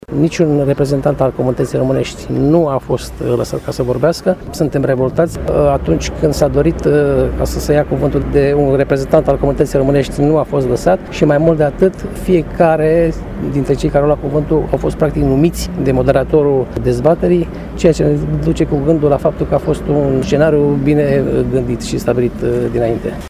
Liderul PNL Covasna, Olimpiu Floroian, care este şi consilier judeţean, a declarat că termenul “dezbatere”, menţionat în program de către organizatori, a fost impropriu, deoarece întâlnirea, la care au participat circa 300 de primari şi consilieri locali şi judeţeni, a fost o “informare” privind aspecte ce vizează comunitatea maghiară: